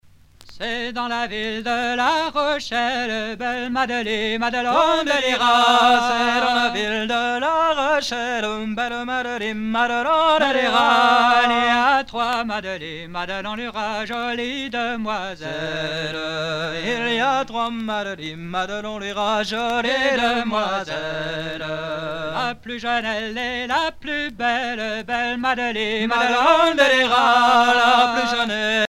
danse : an dro
Genre laisse
Chants à répondre et à danser